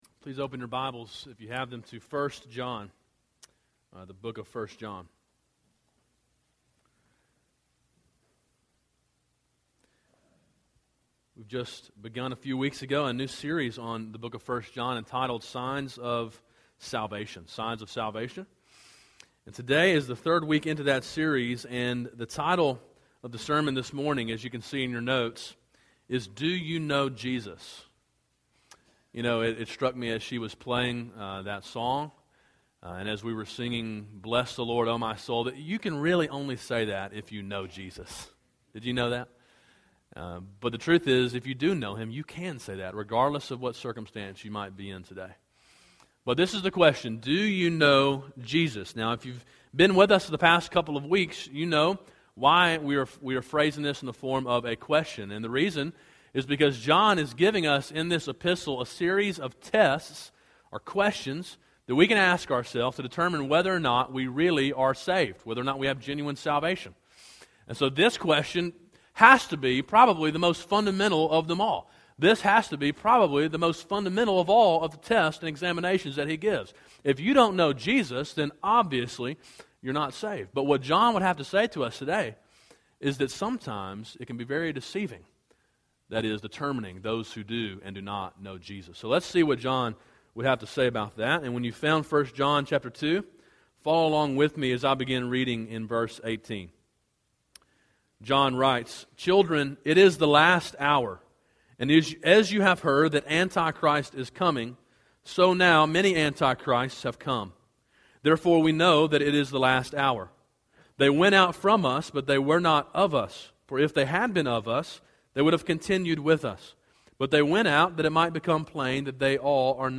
A sermon in a series on the book of 1 John.